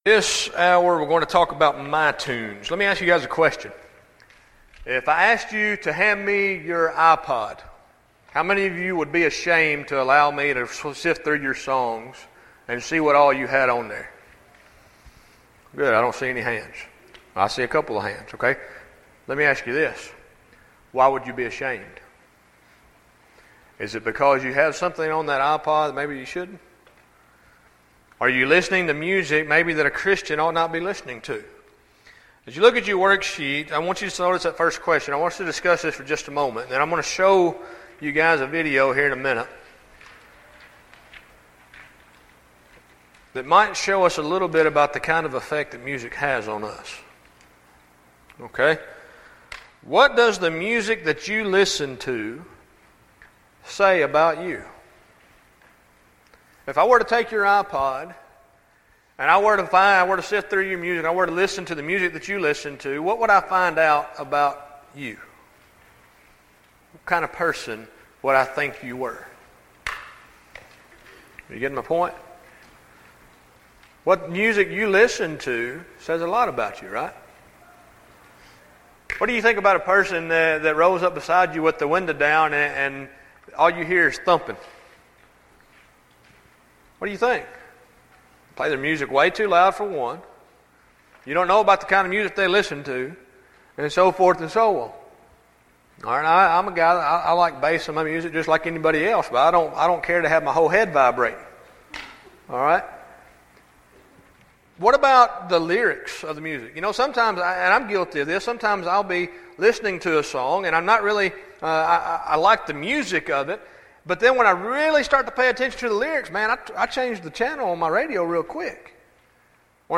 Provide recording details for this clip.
Event: Discipleship University 2012